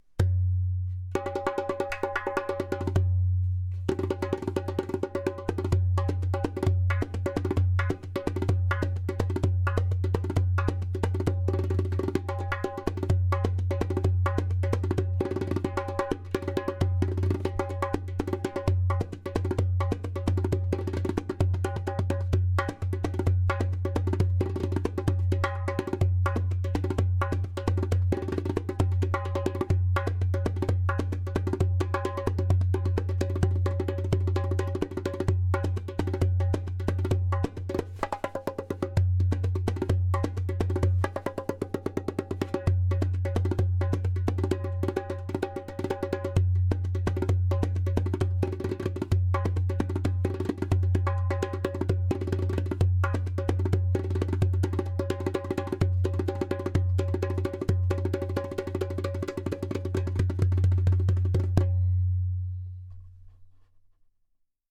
130 BPM:
• Strong and easy to produce clay kik (click) sound
• Deep bass
• Beautiful harmonic overtones.